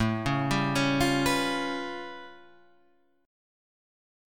A Augmented 9th